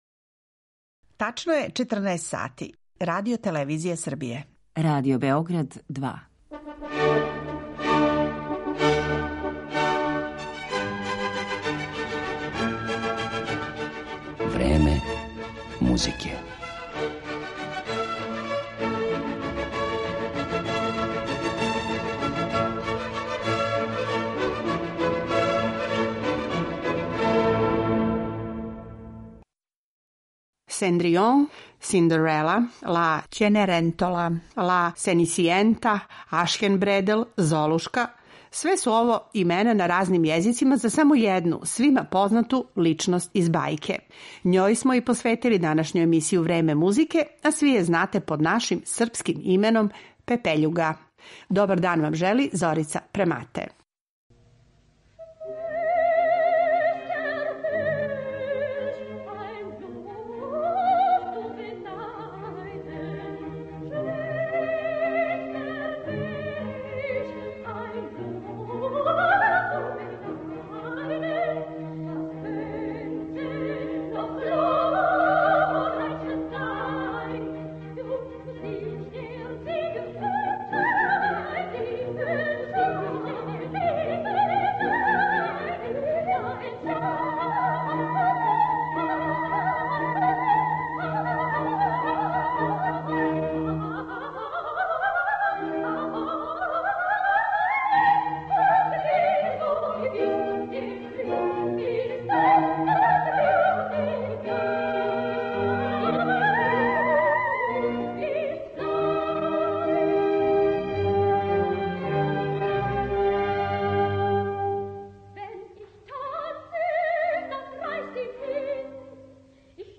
Пепељуга у оперској или балетској музици
Слушаћете како су Пепељугу у својој оперској или балетској музици дочарали, између осталих, и Росини, Масне, Штраус Син и Прокофјев.